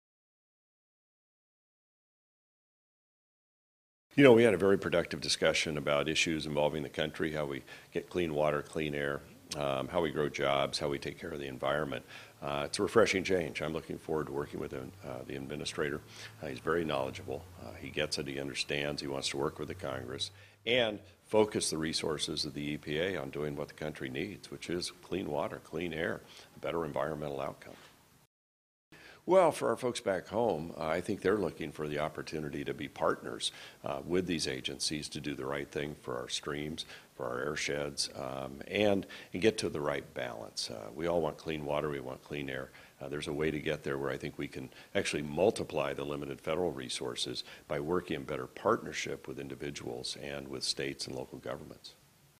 May 3, 2017 - EPA Administrator Scott Pruitt's meeting with Rep. Greg Walden, Washington, DC.
Speaker: Rep. Greg Walden